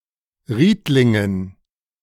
Riedlingen (German pronunciation: [ˈʁiːtlɪŋən]
De-Riedlingen.ogg.mp3